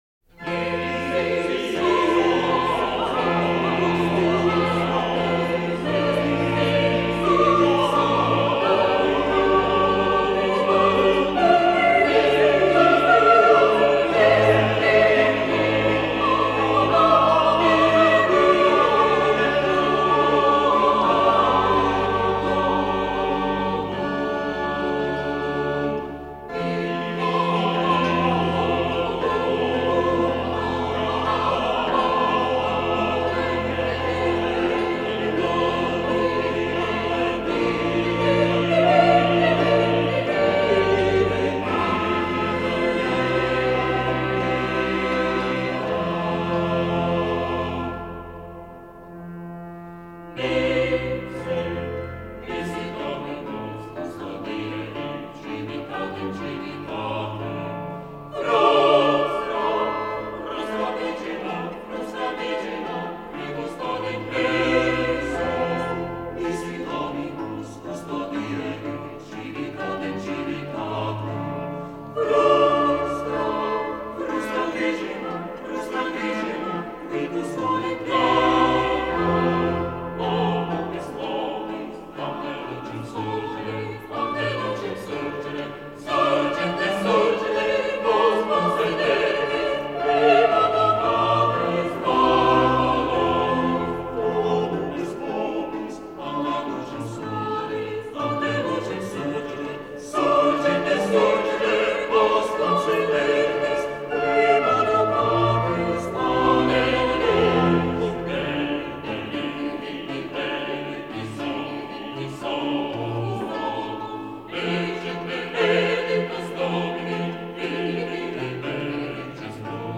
Garīgā vokālā mūzika
Mūzikas ieraksts